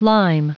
Prononciation du mot lime en anglais (fichier audio)
Prononciation du mot : lime